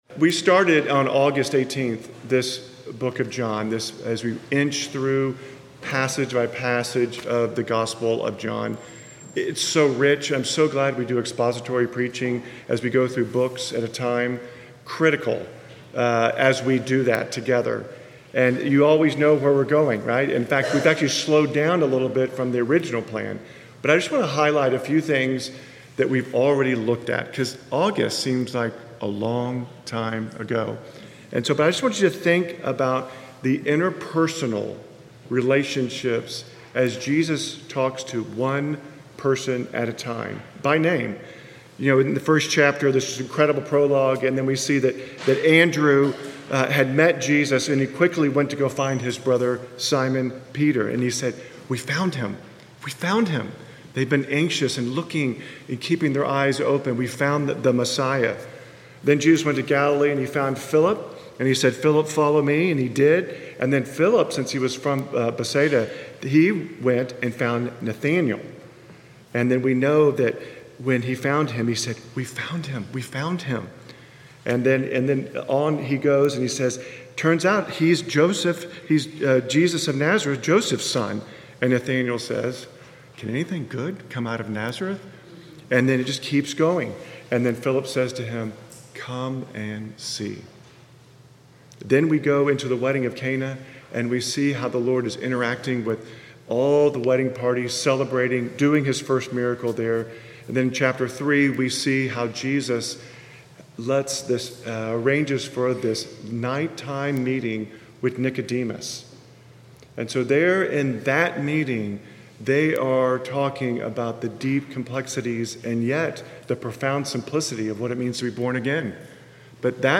Park Cities Presbyterian Church (PCA) in Dallas, TX exists to extend the transforming presence of the Kingdom of our Lord Jesus Christ in Dallas and to the world.